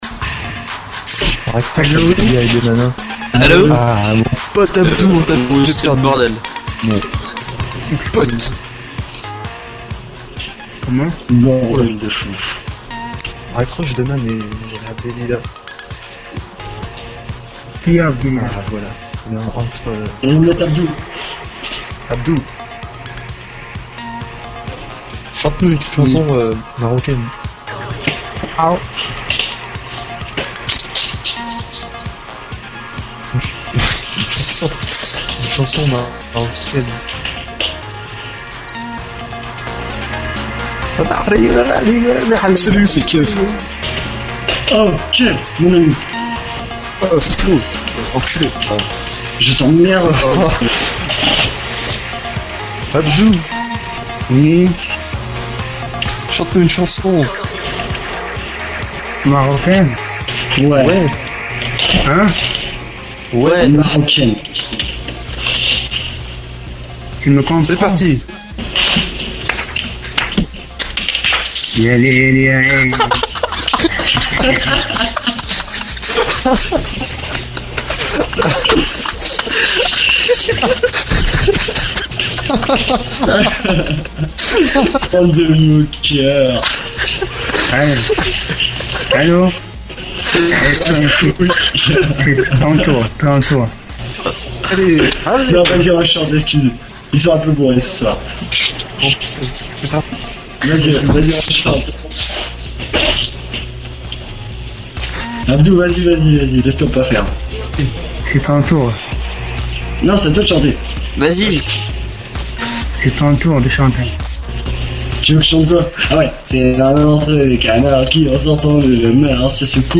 Extrait libre antenne